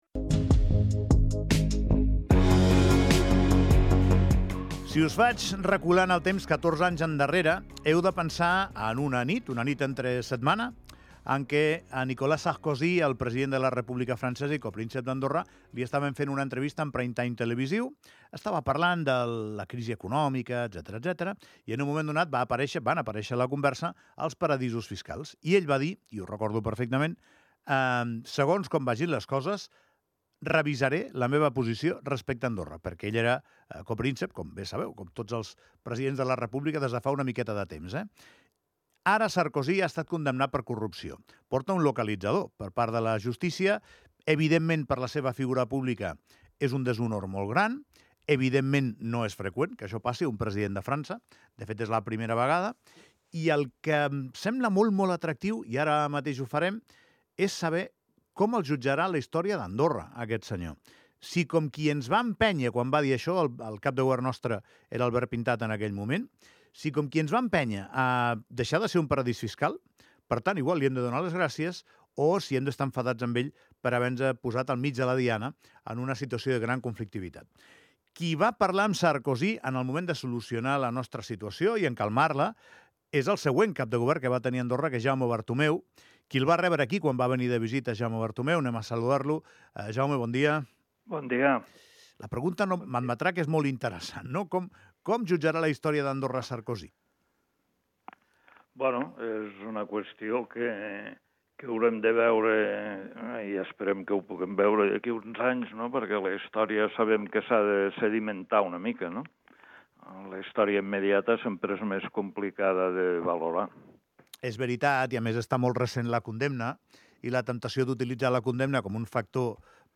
L'exCap Jaume Bartumeu ens parla de Sarkozy